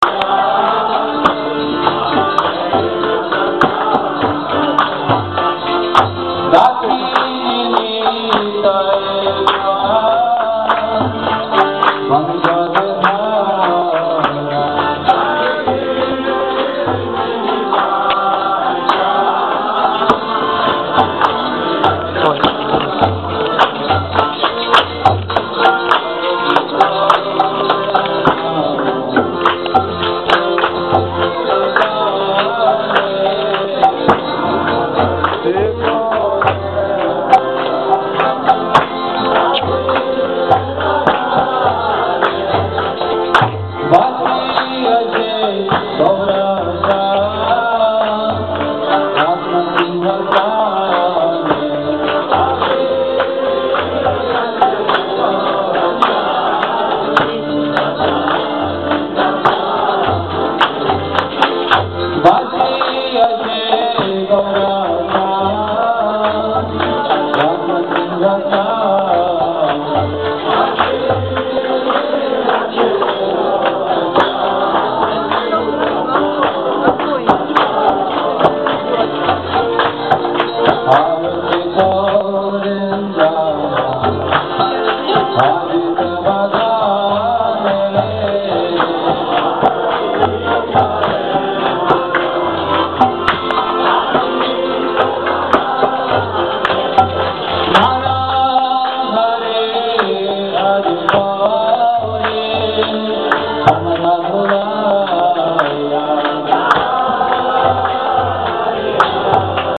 Кришнаиты продолжают играть на экзотических музыкальных инструментах и петь на загадочном языке: Песнь кришнаитов Песнь кришнаитов 2 А далее по программе, как и обещали, следует «халявная» вегатарианская еда.